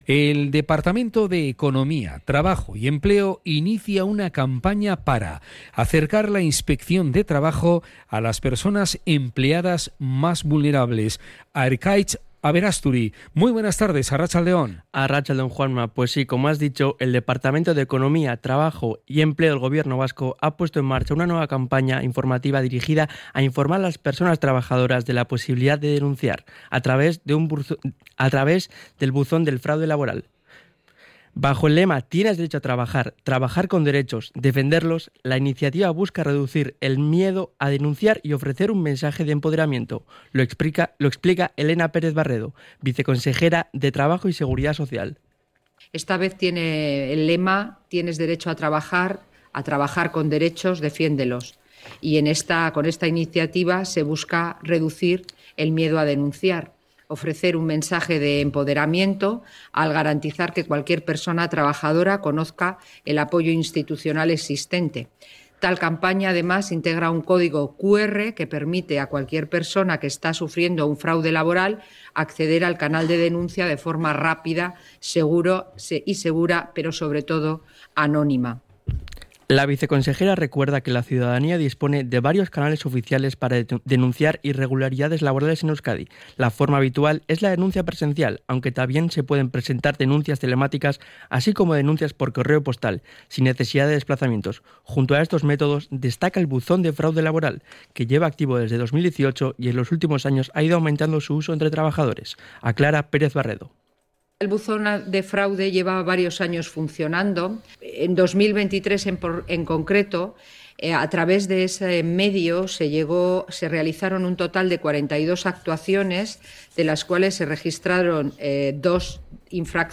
CRONICA-FRAUDE-LABORAL.mp3